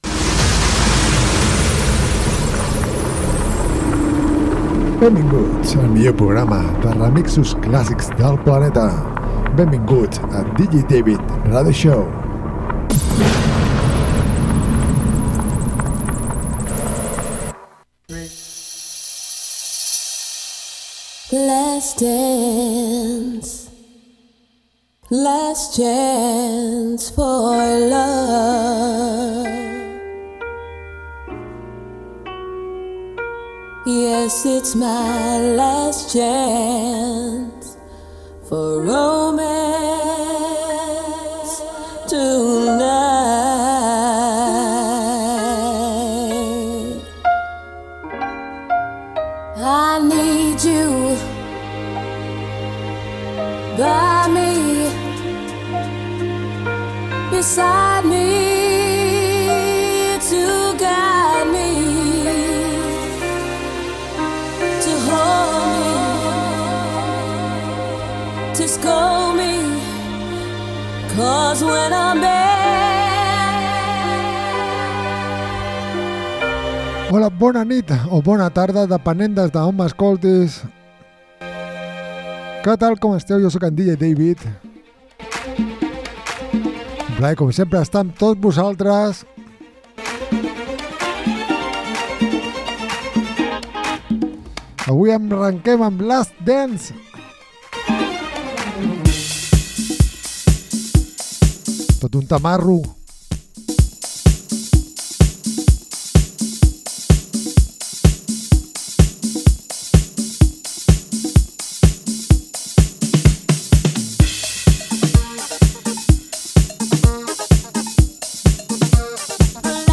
programa de ràdio
remixos classics